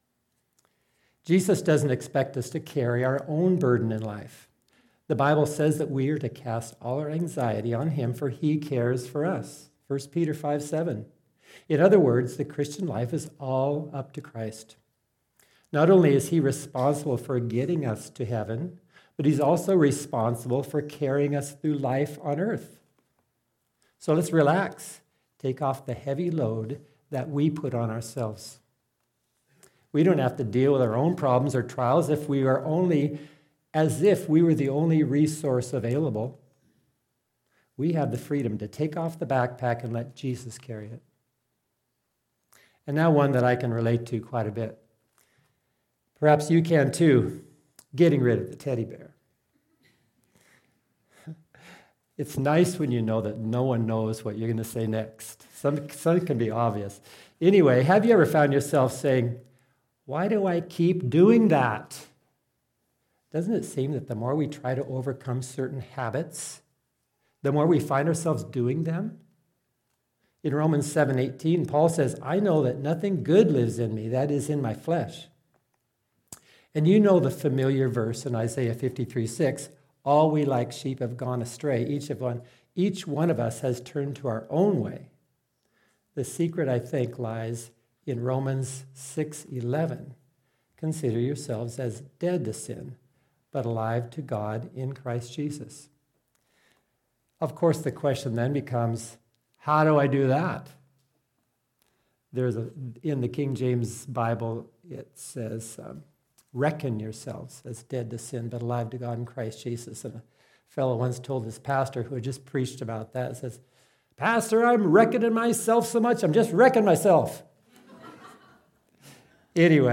Some of the early audio seems to be missing, sorry!